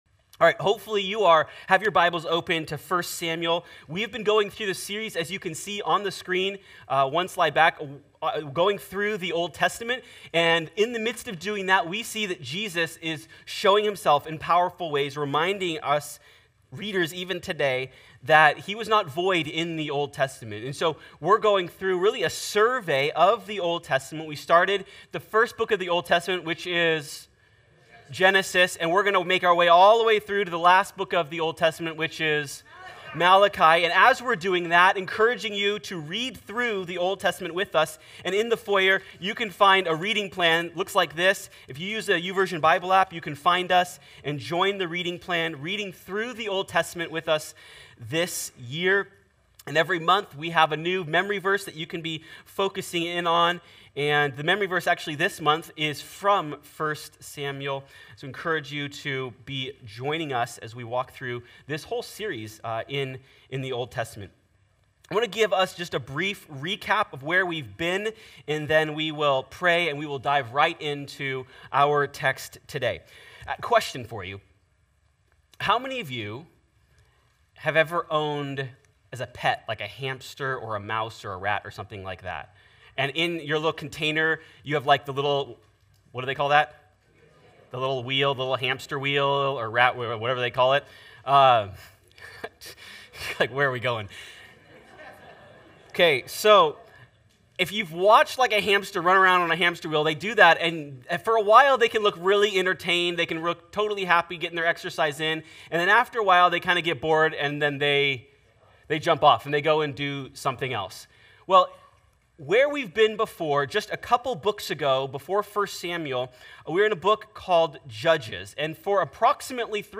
Join us as our Elders teach and we read through the entire Old Testament in one year!